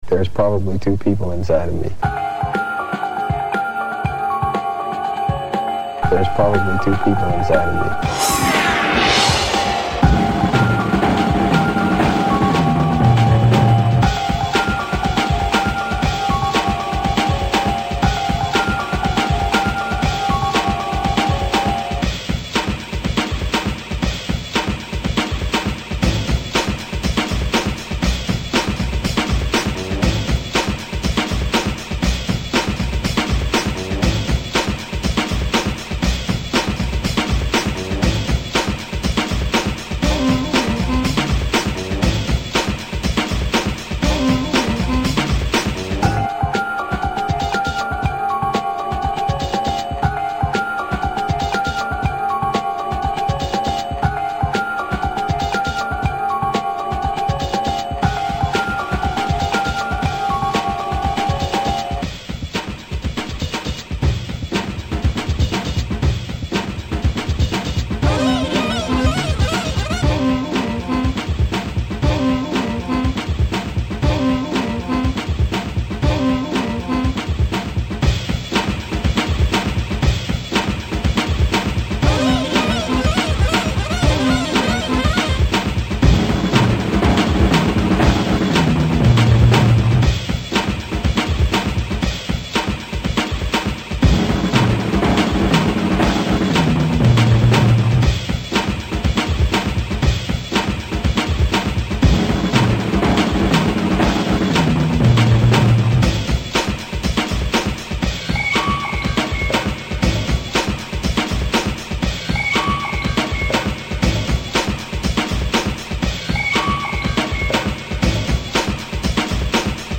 Hi-Phat Loop Salad